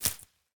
Minecraft Version Minecraft Version 1.21.5 Latest Release | Latest Snapshot 1.21.5 / assets / minecraft / sounds / block / cactus_flower / break3.ogg Compare With Compare With Latest Release | Latest Snapshot
break3.ogg